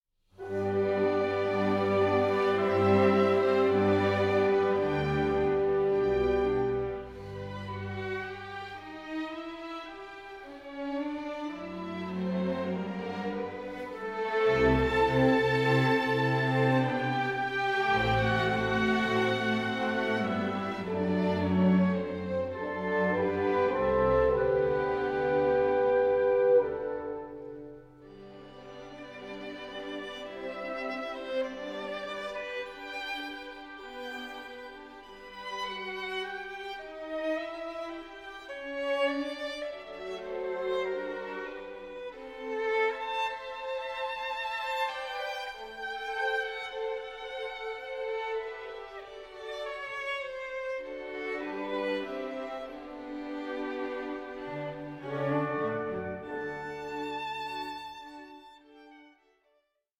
Concerto for Violin & Orchestra No. 2 in D Major
Andante 8:16